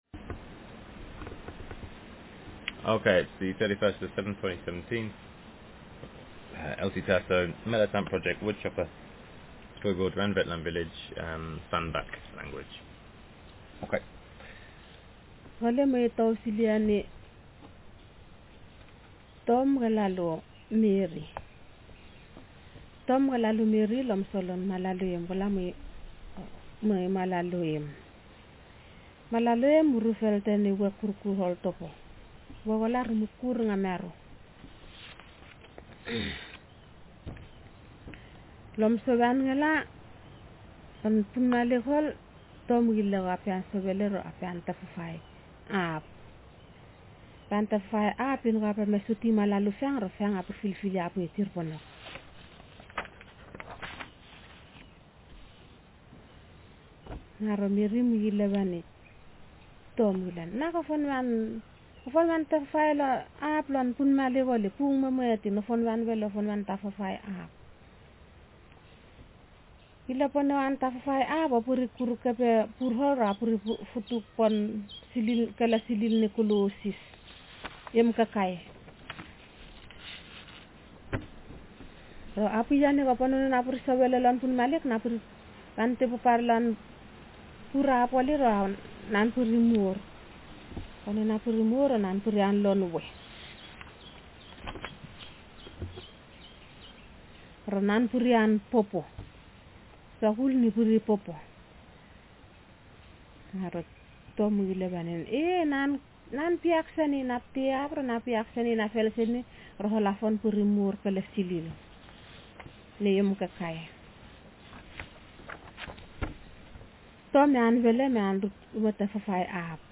Speaker sexf
Text genrestimulus retelling
doreco_orko1234_SB_ET1_woodchopper.mp3